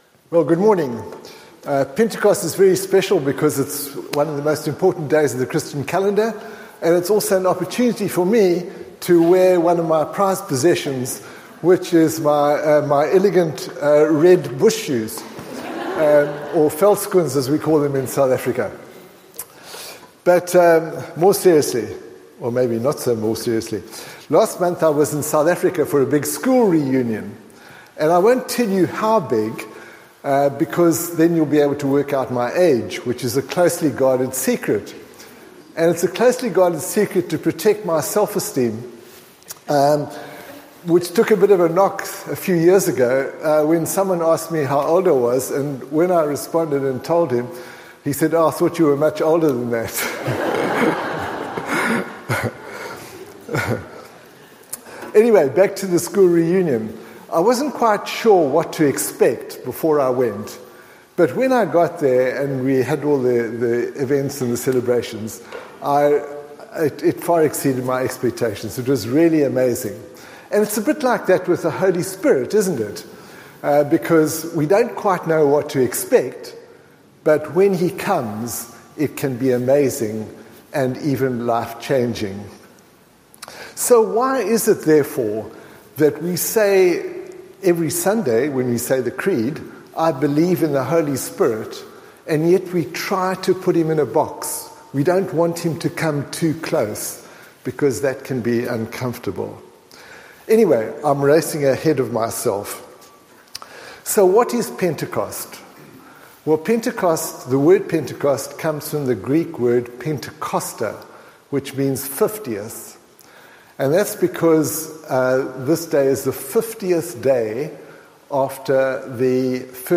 Recordings of sermons preached at St Andrew's, along with talks given at other times, are available from this site.